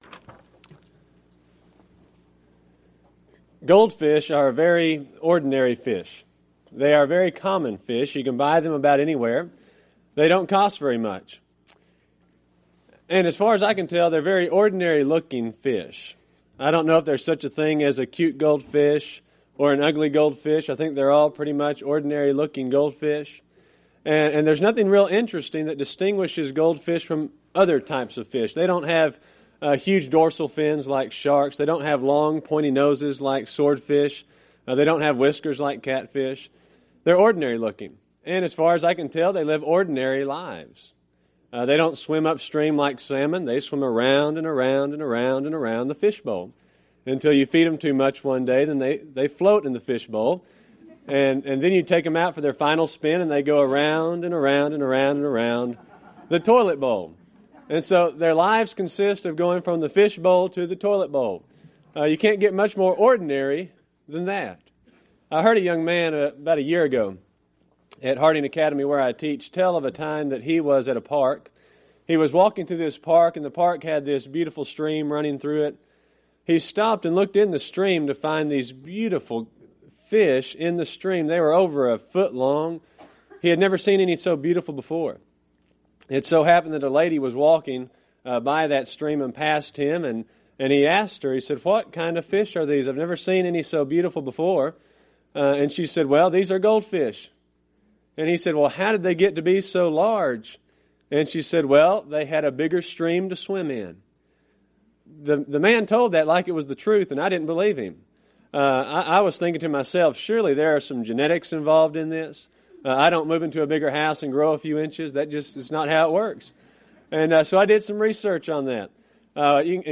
Event: 2003 Annual Shenandoah Lectures Theme/Title: Great Questions in the Bible